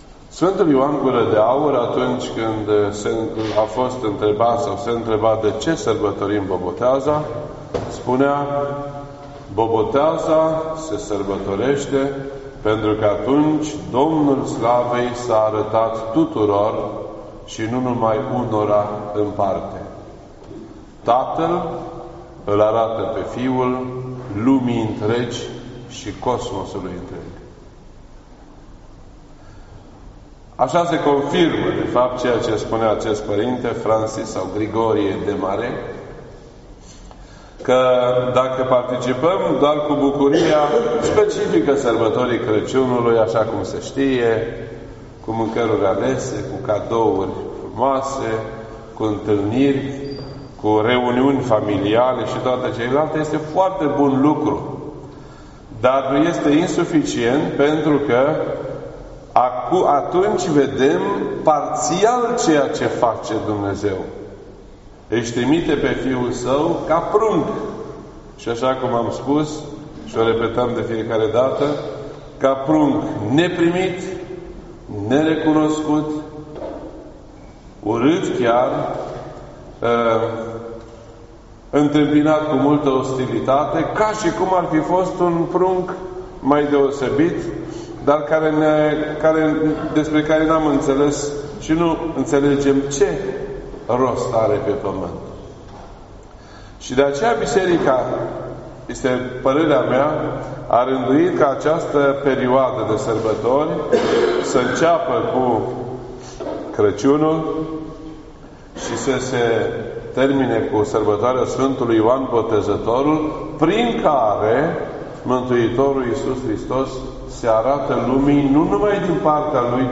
2020 at 4:15 PM and is filed under Predici ortodoxe in format audio .